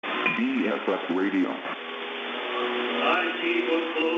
UNID Piratensender 6670 Khz
Deutscher Hip Hop aus Hamburg.